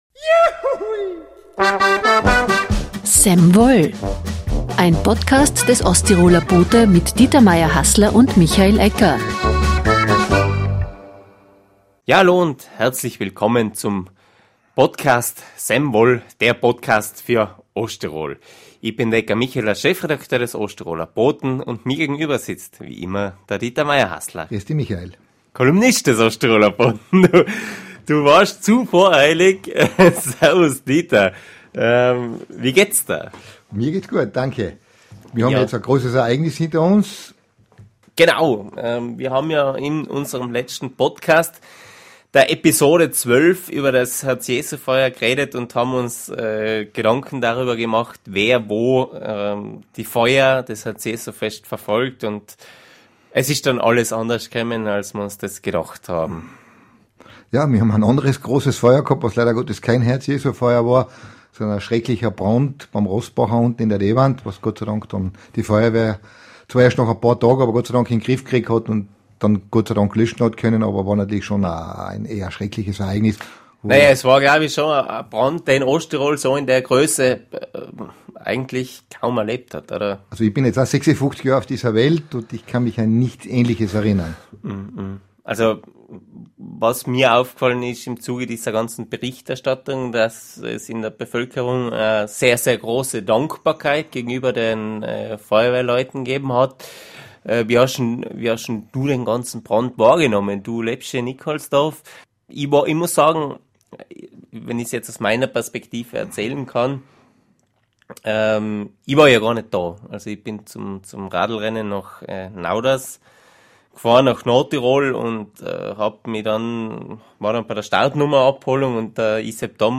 im Talk.